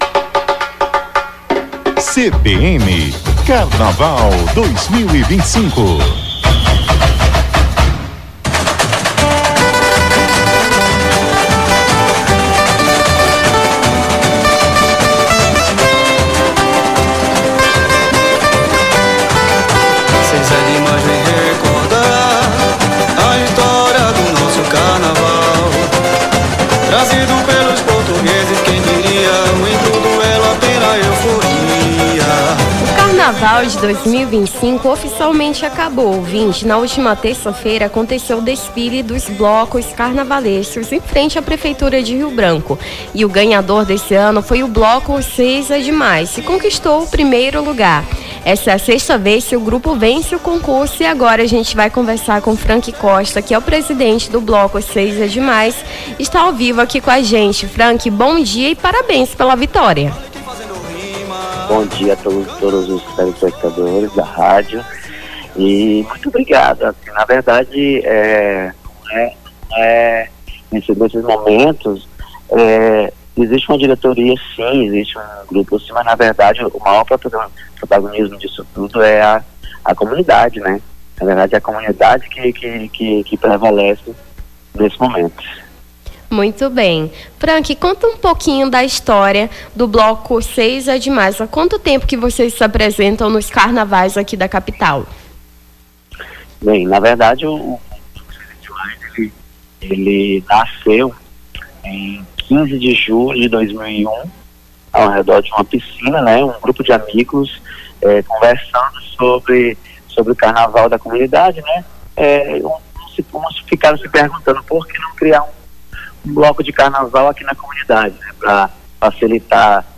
Nome do Artista - CENSURA - ENTREVISTA CAMPEÃO BLOCO 6 É D+ (06-03-25).mp3